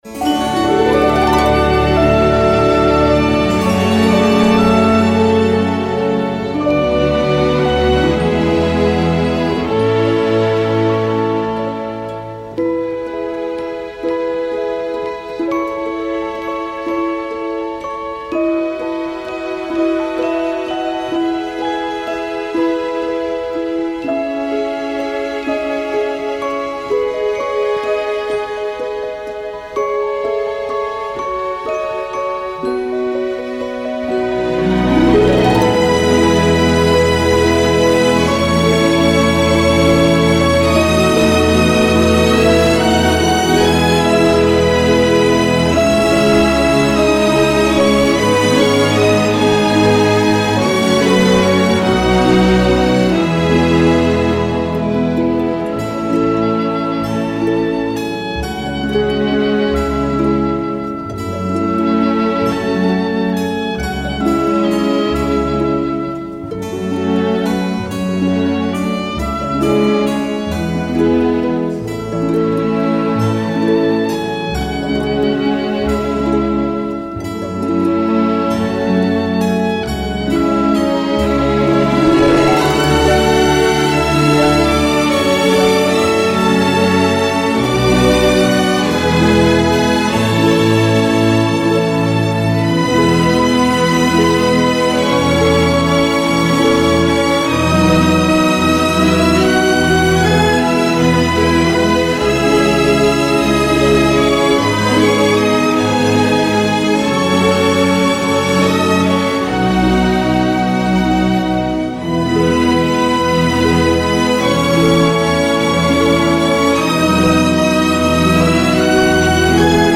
un score bien charmant, nourri à un jazz nonchalant